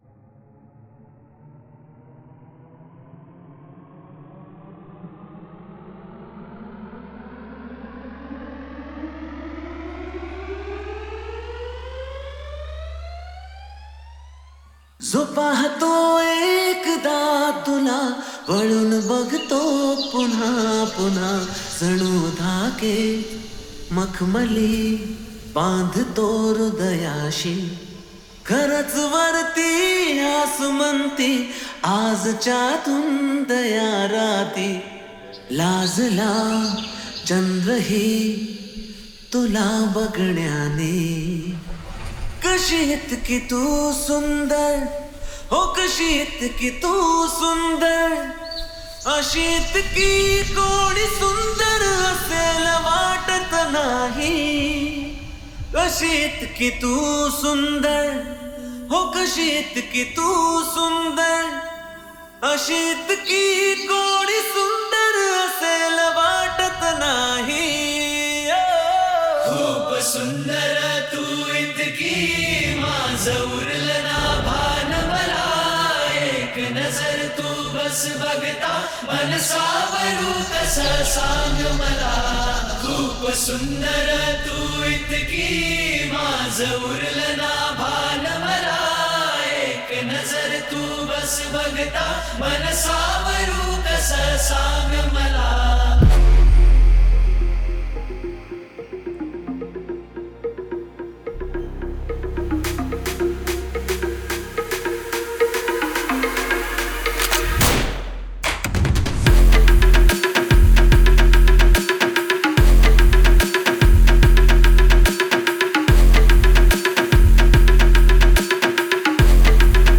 DJ ALBUM